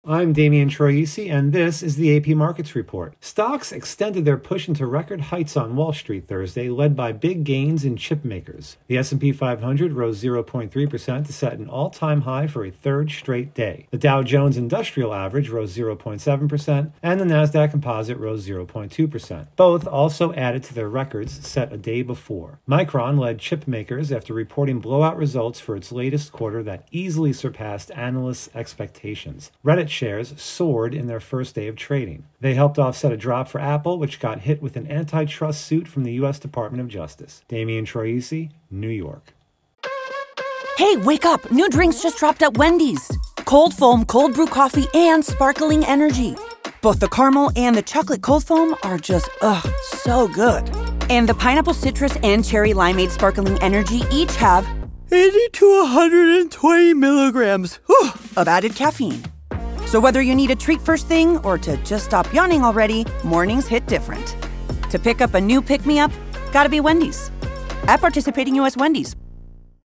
((self intro))